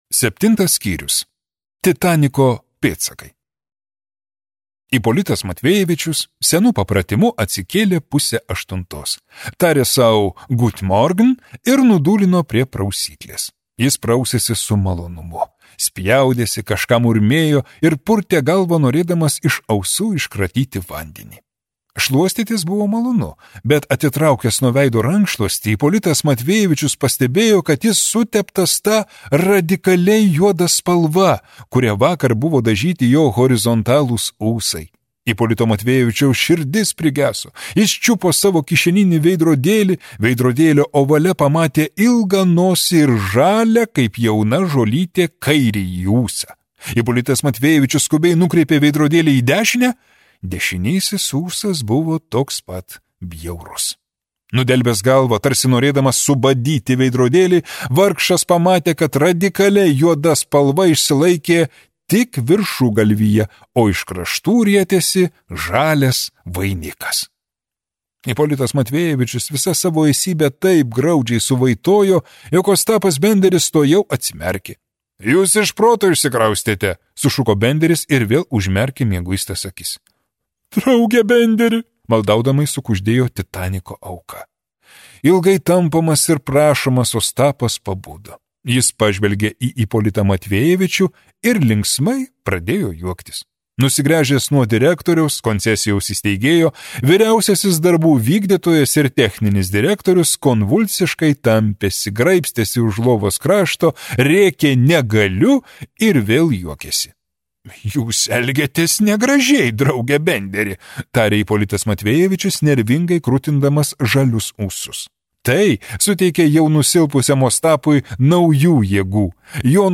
Audio knyga